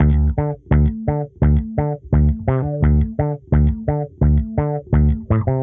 Index of /musicradar/dusty-funk-samples/Bass/85bpm